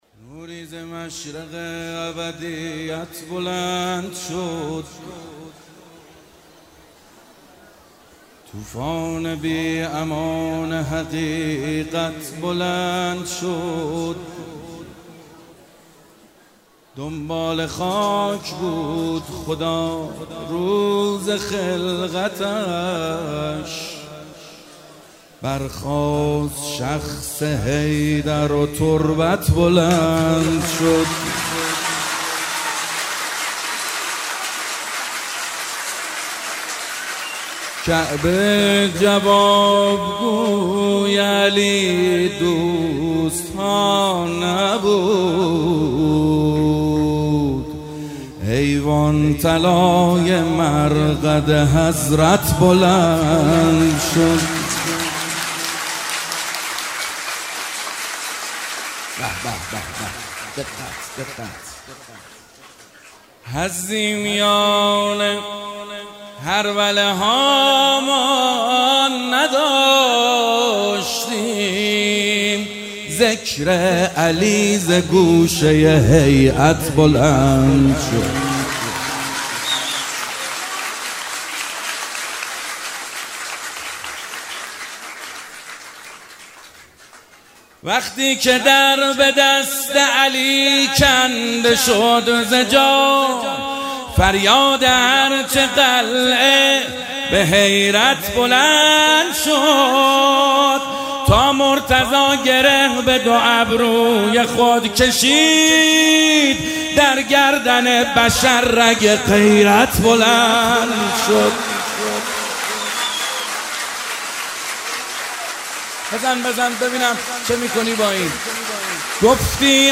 شب نیمه شعبان - مدح خوانی - محمد حسین حدادیان
شب نیمه شعبان